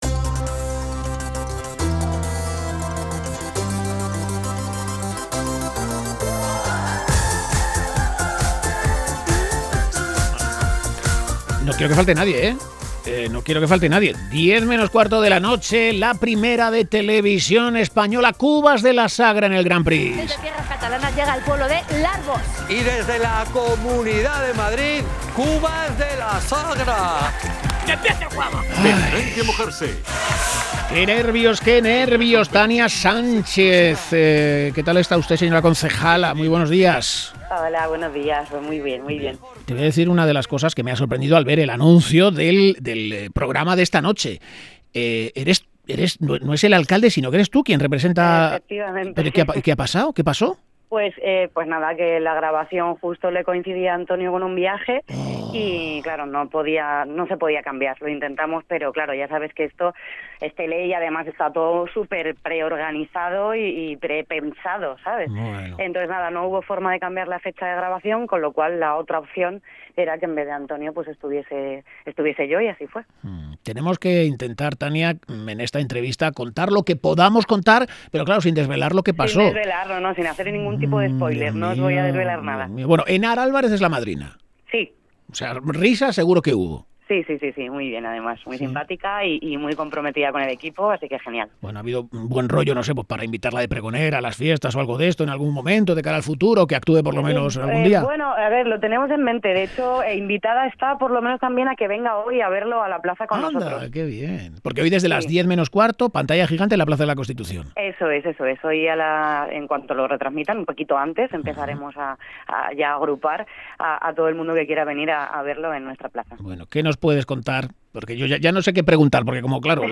Entrevistamos a la concejala Tania Sánchez, que encabeza la expedición de Cubas de la Sagra en el programa televisivo Grand Prix